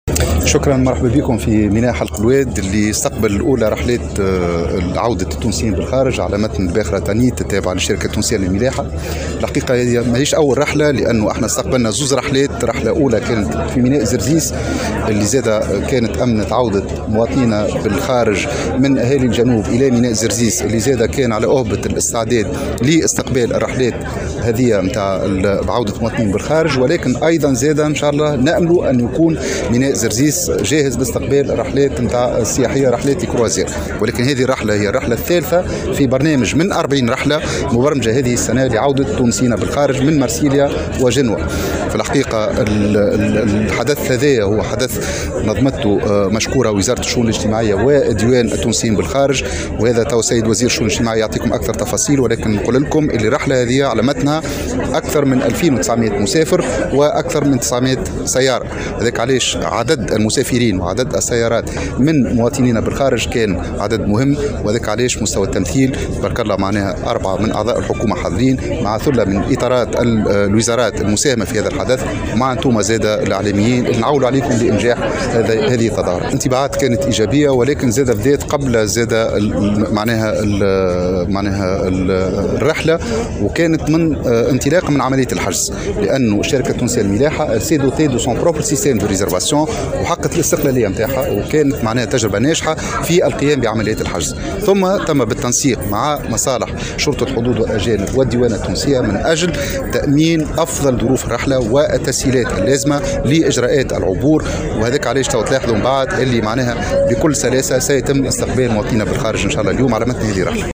وأكد وزير النقل ربيع المجيدي في تصريح للجوهرة أف أم، لدى إشرافه رفقة وفد حكومي يضم أيضا وزيري الشؤون الاجتماعية والسياحة على استقبال هذه الرحلة، أن المسافرين سيتمتعون بكل التسهيلات في إجراءات العبور بالتنسيق مع مصالح شرطة الحدود والأجانب والديوانة التونسية.
وزير النقل